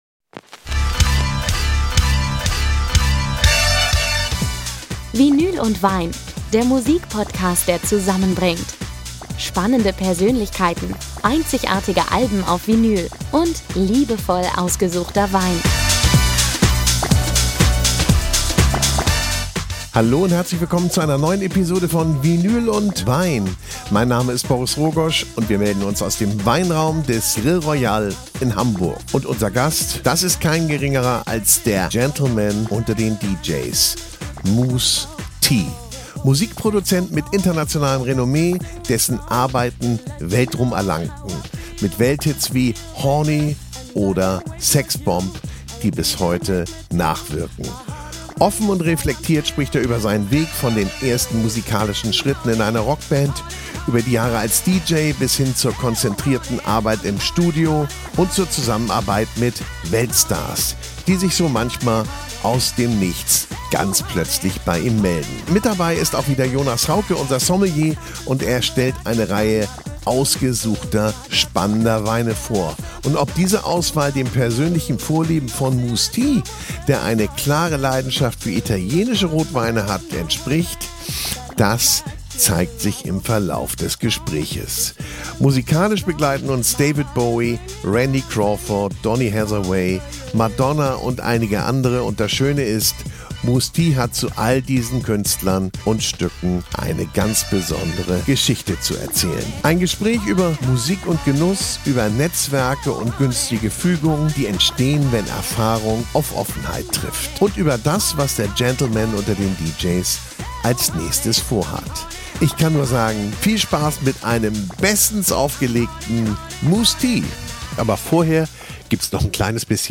Aufgezeichnet wurde diese Episode im Weinraum des Grill Royal Hamburg – ein Ort, der wie gemacht ist für Gespräche dieser Art: konzentriert, genussvoll und mit Raum für Tiefe. Musikalisch spannt sich der Bogen von Soul, Disco und Pop – begleitet von persönlichen Anekdoten aus seinem Musikerleben.